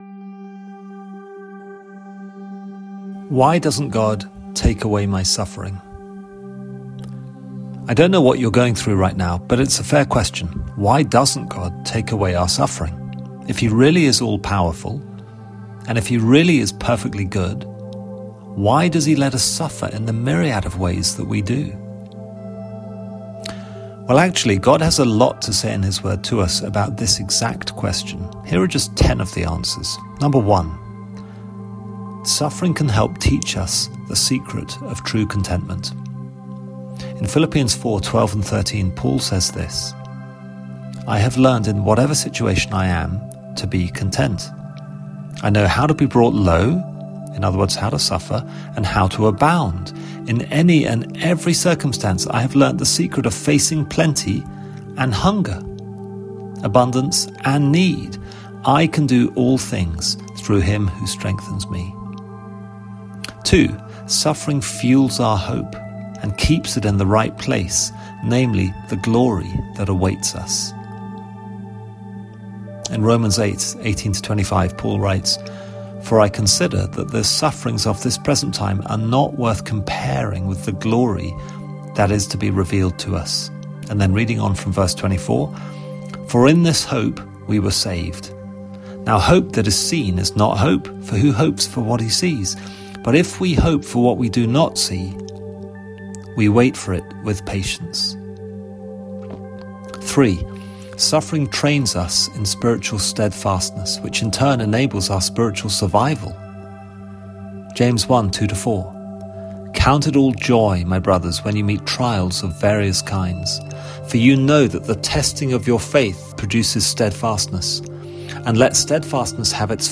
Download Download Pastoral Current Sermon Why Doesn't God Take Away My Suffering?